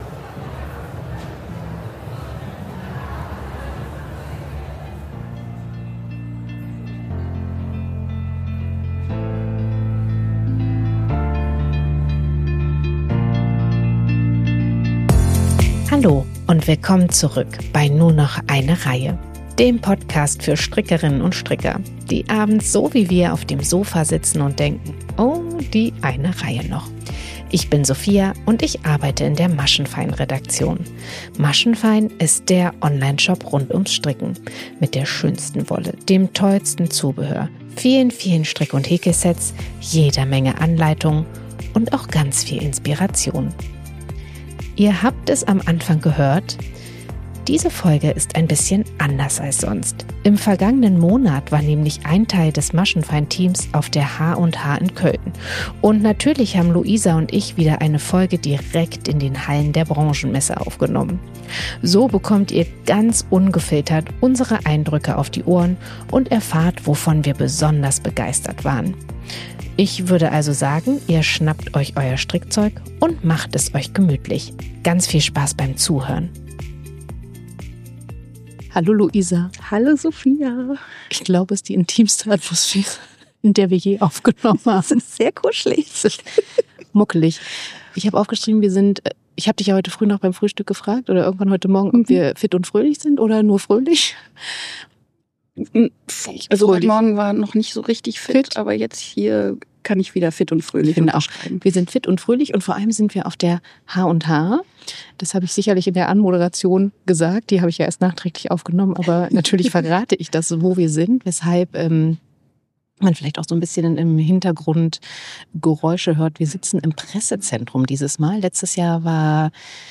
Aber so ist das Jahr für Jahr mit der h+h: Wir fiebern wochenlang auf die Branchenmesse hin und dann geht sie viel zu schnell vorbei.